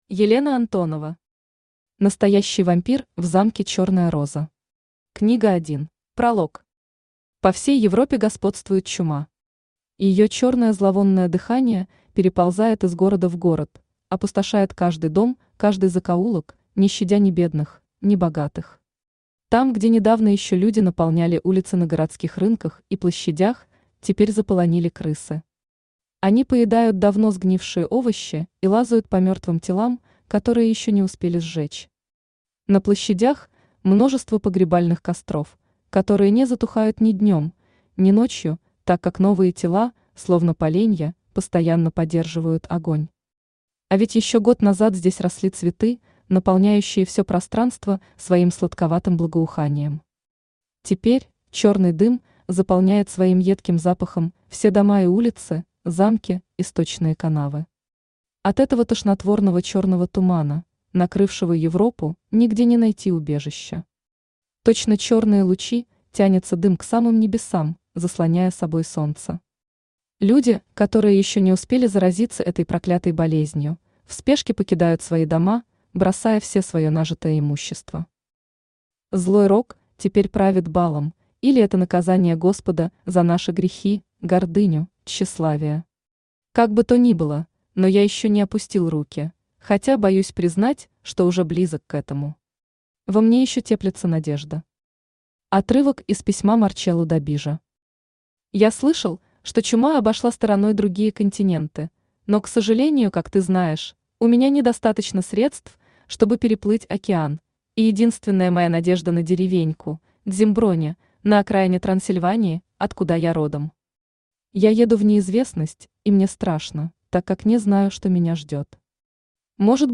Аудиокнига Настоящий вампир в замке Черная роза. Книга 1 | Библиотека аудиокниг
Книга 1 Автор Елена Антонова Читает аудиокнигу Авточтец ЛитРес.